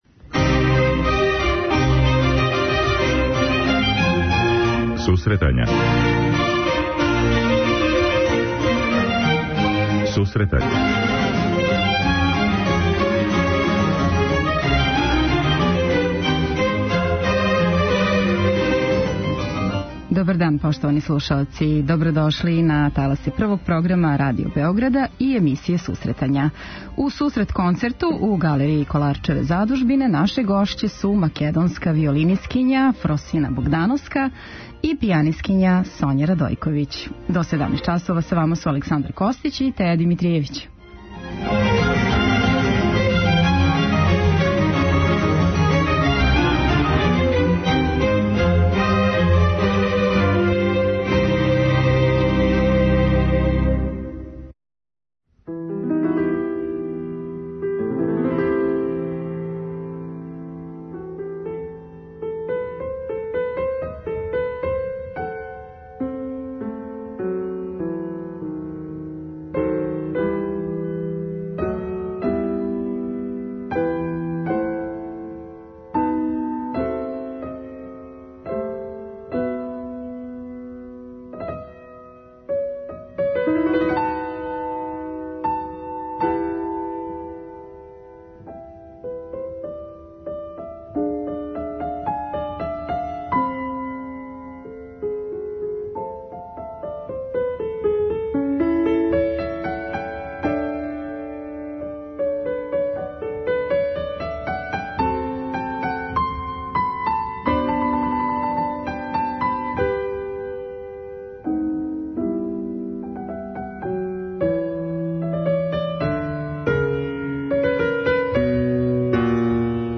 преузми : 10.83 MB Сусретања Autor: Музичка редакција Емисија за оне који воле уметничку музику.